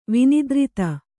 ♪ vinidrita